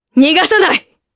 Naila-Vox_Skill1_jp.wav